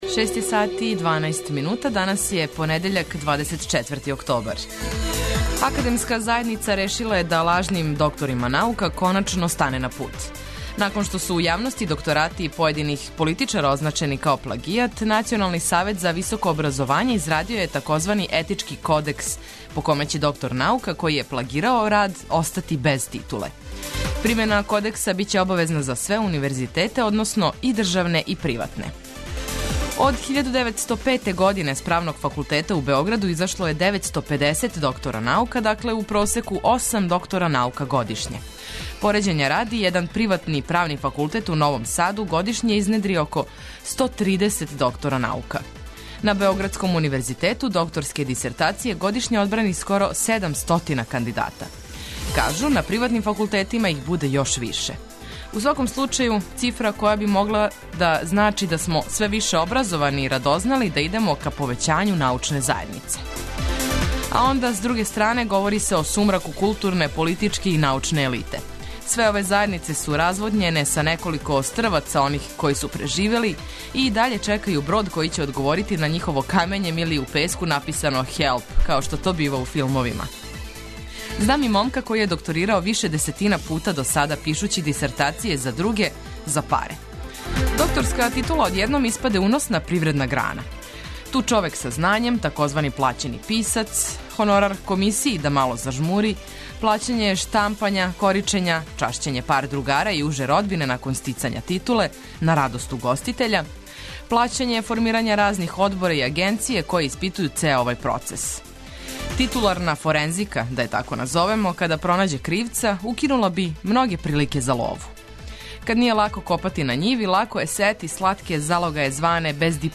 Пратимо дешавања широм Србије, спортске, сервисне информације, али пре свега прве сате јутра улепшавамо уз много добре музике!